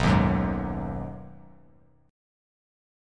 B6 Chord
Listen to B6 strummed